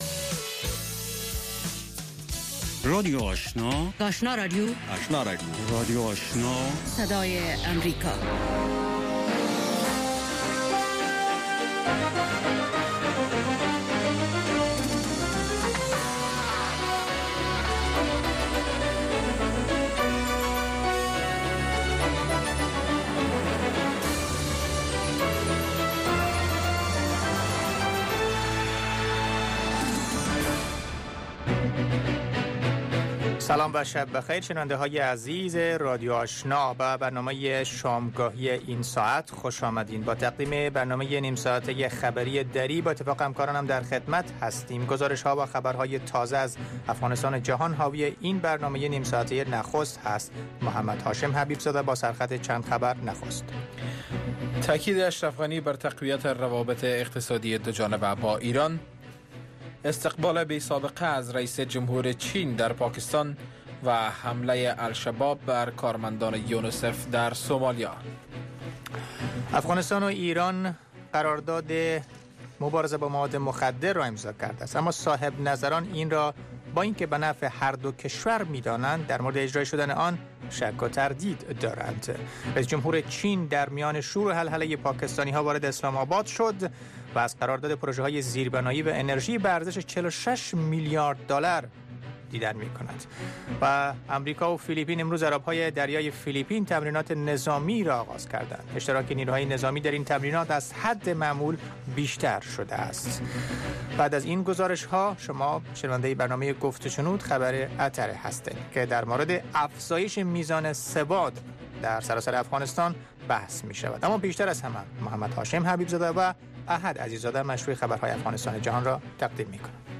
اولین برنامه خبری شب